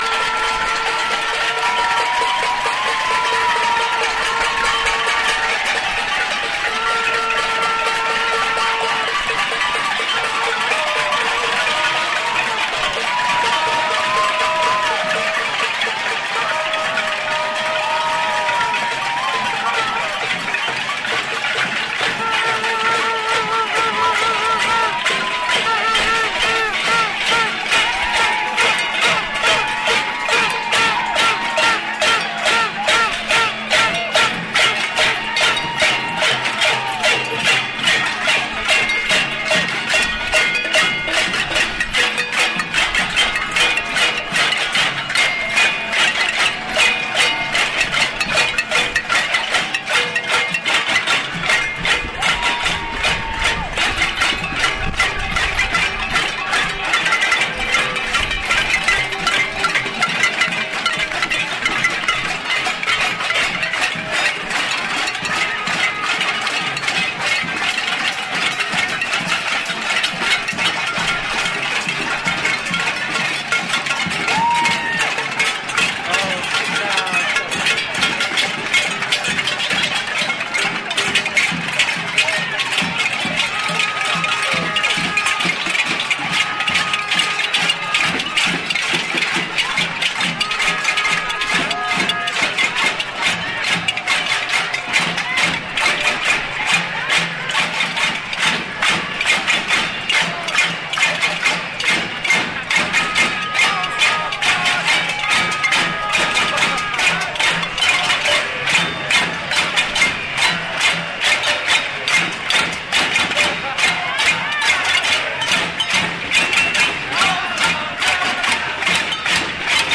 Casserole March. Villeray, Montreal. 30 May 2012.